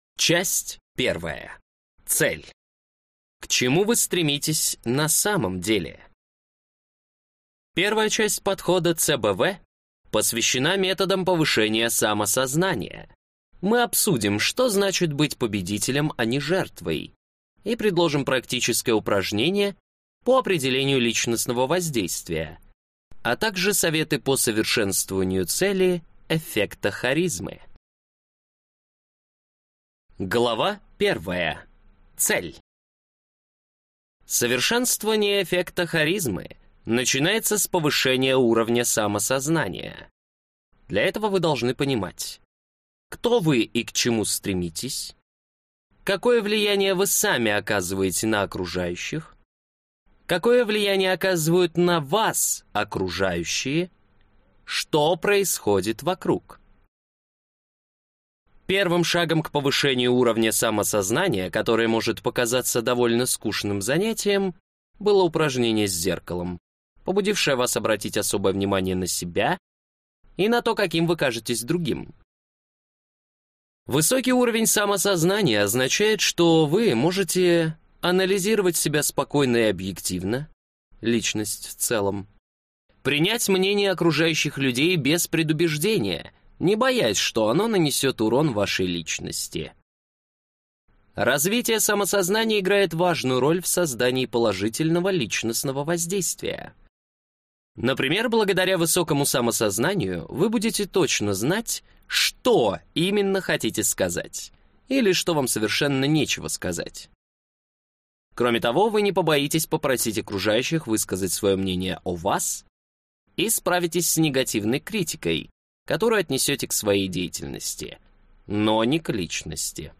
Аудиокнига Харизма. Искусство производить сильное и незабываемое впечатление | Библиотека аудиокниг